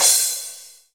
Index of /90_sSampleCDs/Sound & Vision - Gigapack I CD 1 (Roland)/CYM_CRASH mono/CYM_Crash mono
CYM CRA17.wav